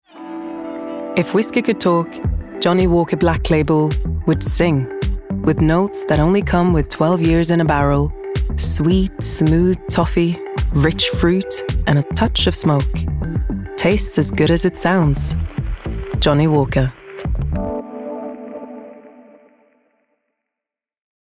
• Female
Johnnie Walker. Cool, Confident, Playful